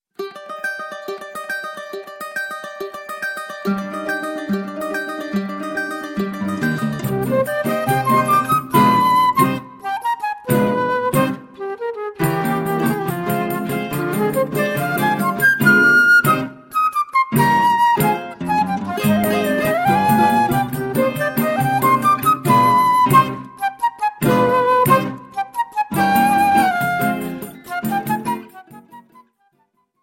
flute
Choro ensemble